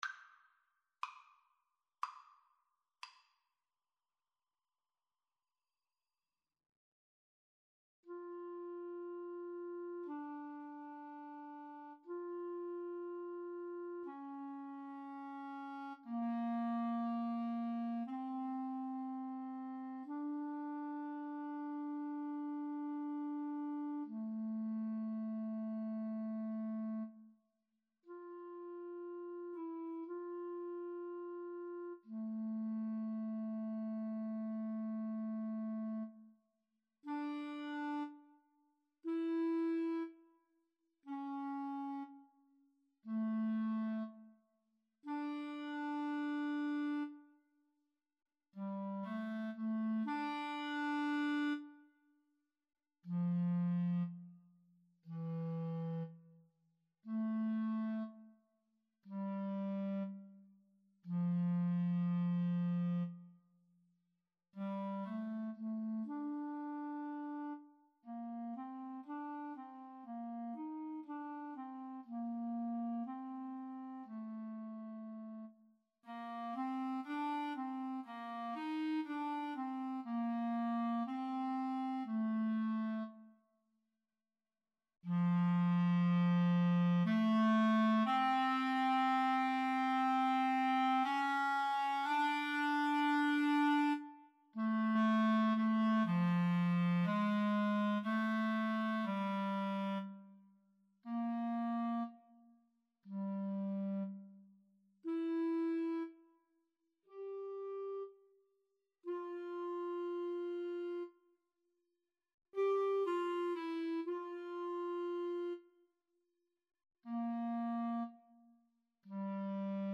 4/4 (View more 4/4 Music)
Adagio non troppo